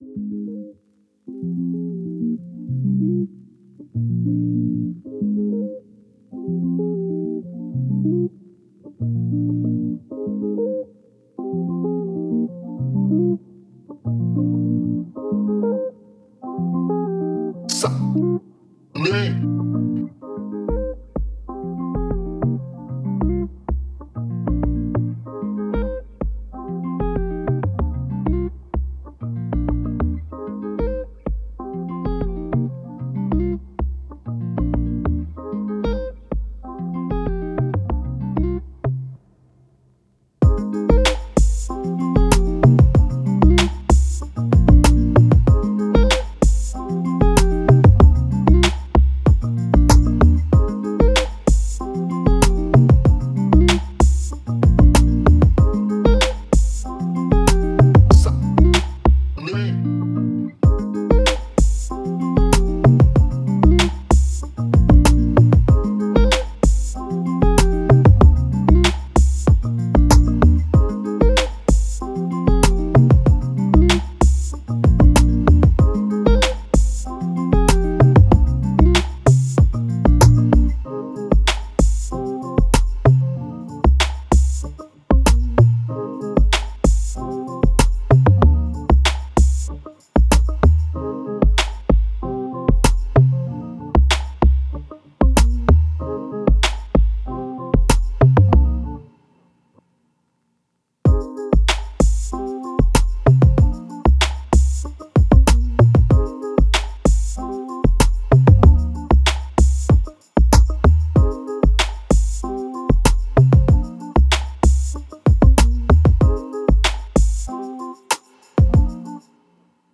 Positive, Chill, Vibe
Acoustic Guitar, Eletric Guitar, Drum, Strings